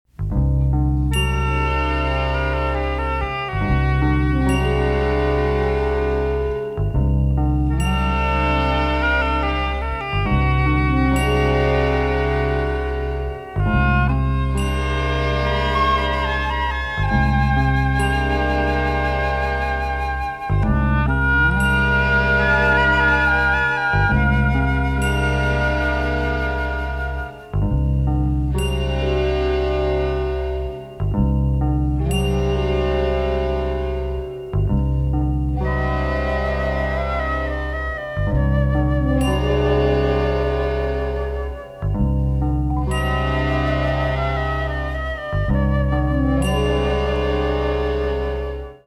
monaural sound from master tapes